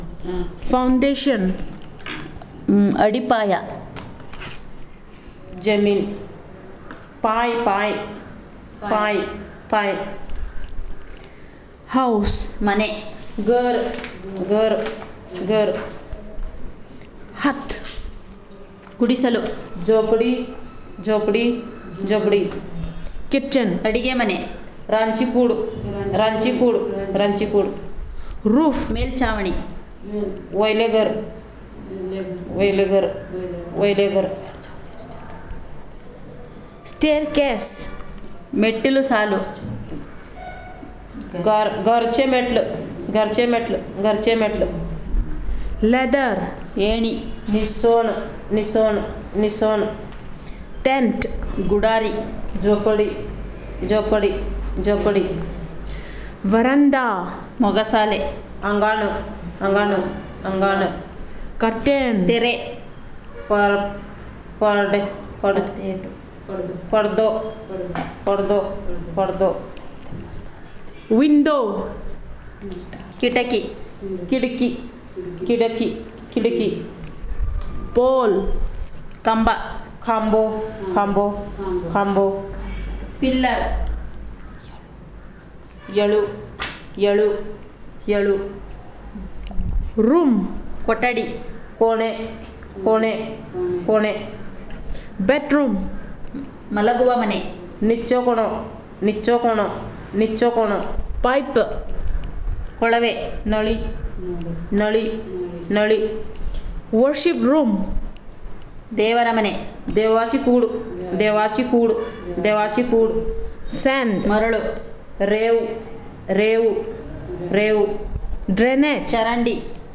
Elicitation of words about housing and related